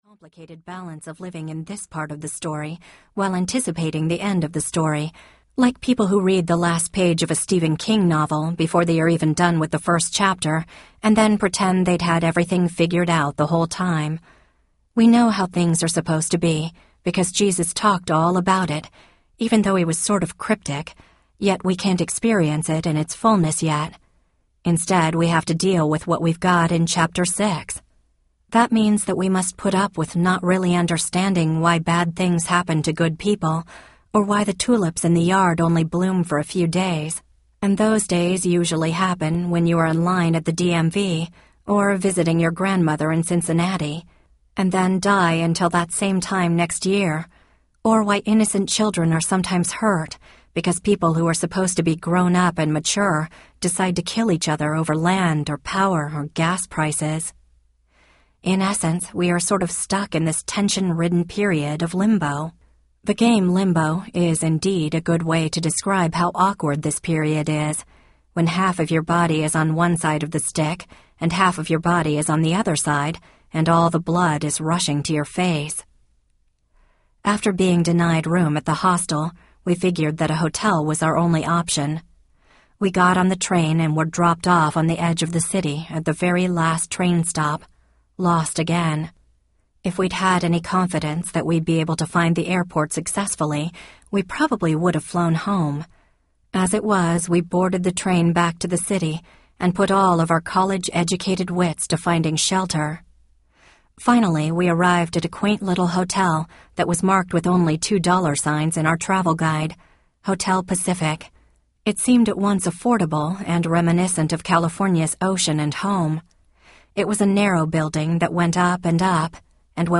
Whirlybirds and Ordinary Times Audiobook
Narrator
5.55 Hrs. – Unabridged